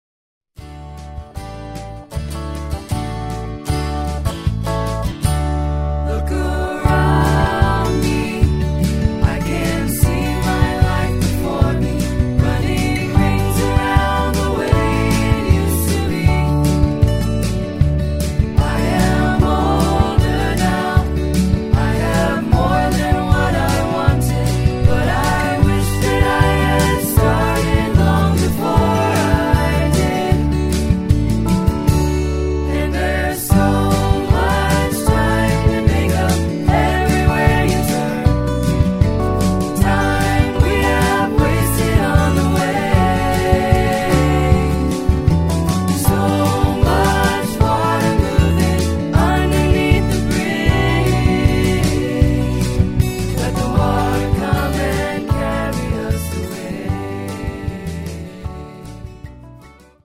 rich, heartfelt group harmony